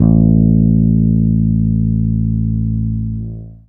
Long Bass Guitar.wav